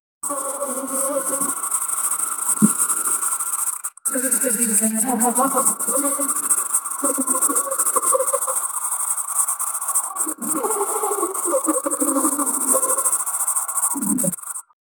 a-talking-and-laughing-hu-fa6mhw7g.wav